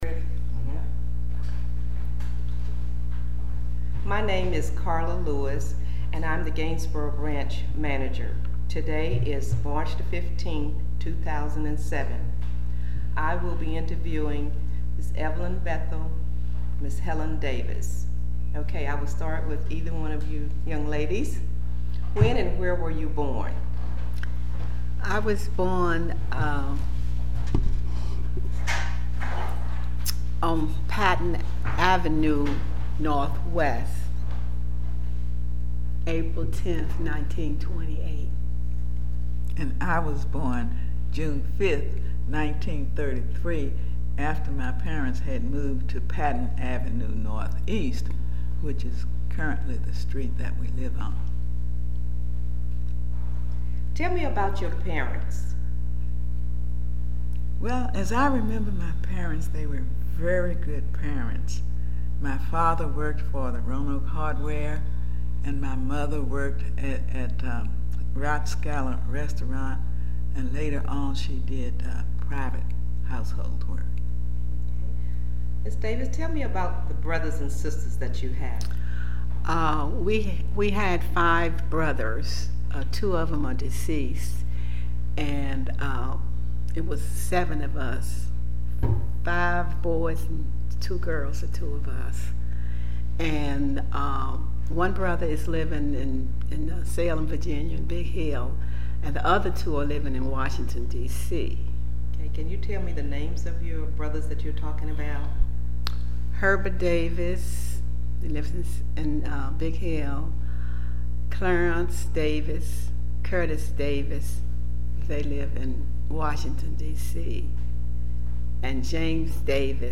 Location: Gainsboro Branch Library
Neighborhood Oral History Project